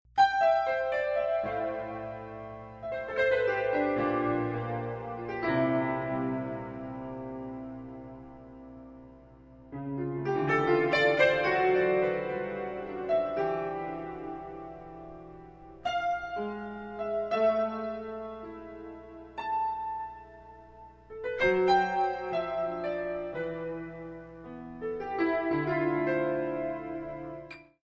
Recorded Live in Japan